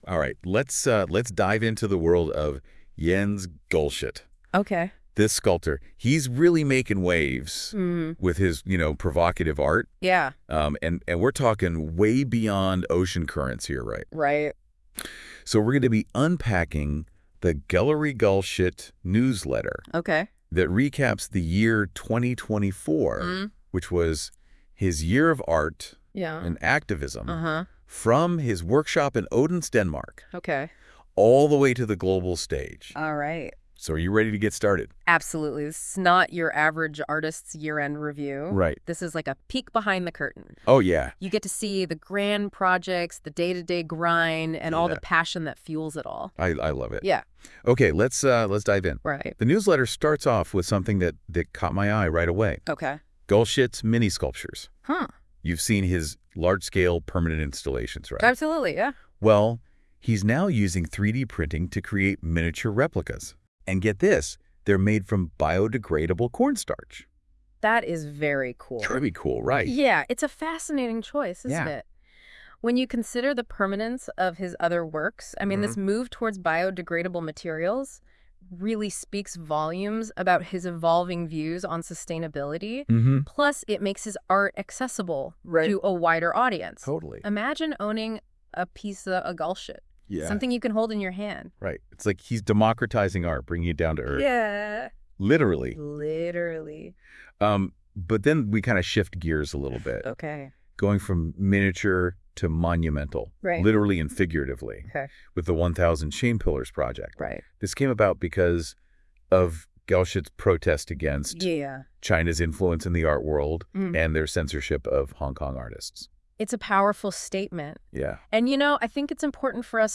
We reflect on 2024 – and unveil plans for 2025 Listen to the newsletter as AI podcast !